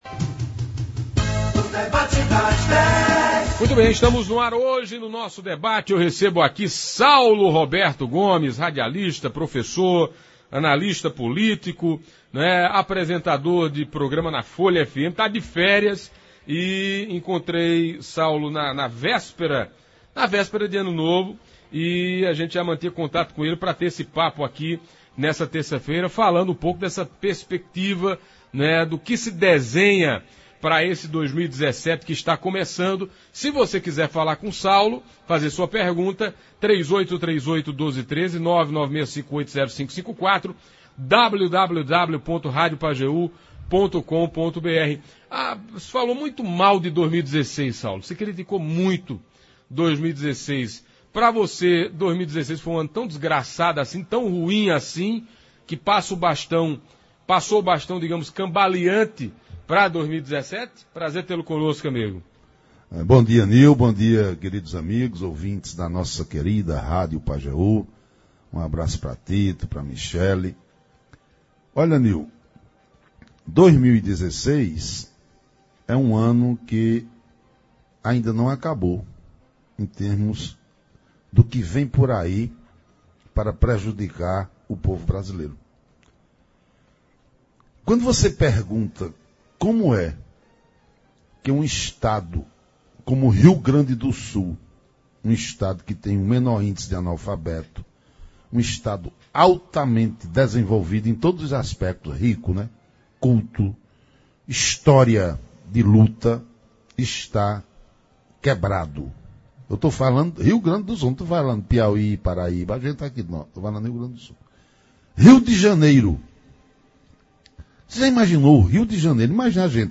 Ouça abaixo na íntegra como foi o debate de hoje: